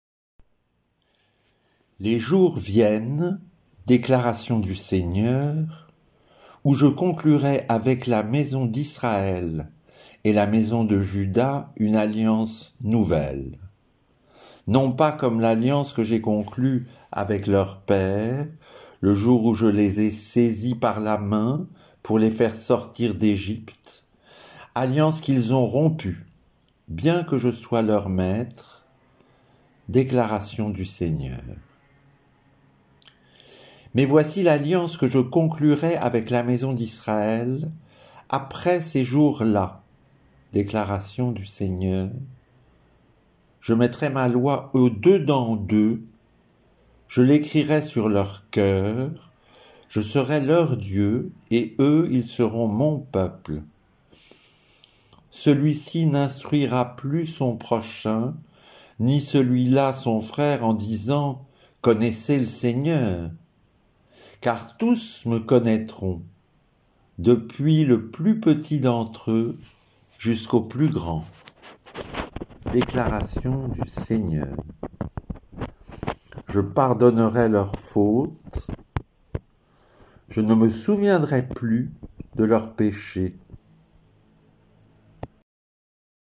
Mais surtout, elle sera plus silencieuse. Plus en harmonie avec ce culte où nous ne chantons pas.